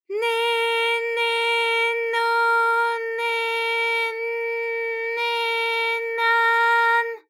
ALYS-DB-001-JPN - First Japanese UTAU vocal library of ALYS.
ne_ne_no_ne_n_ne_na_n.wav